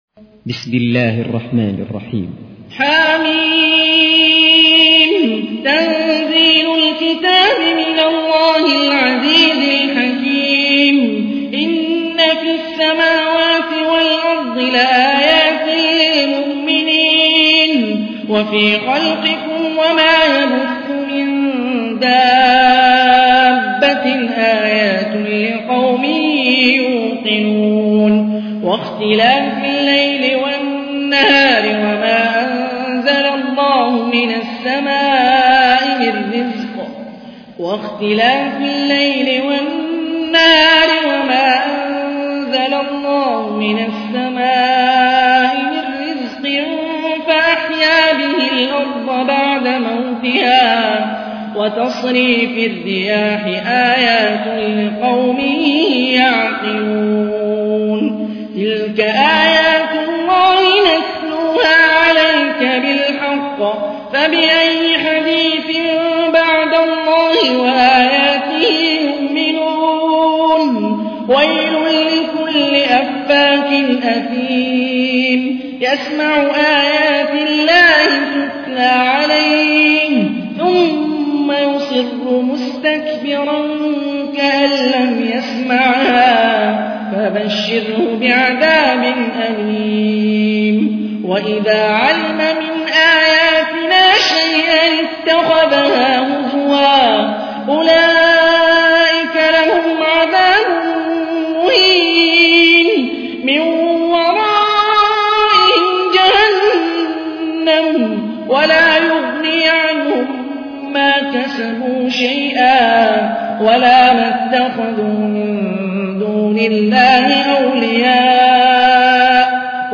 تحميل : 45. سورة الجاثية / القارئ هاني الرفاعي / القرآن الكريم / موقع يا حسين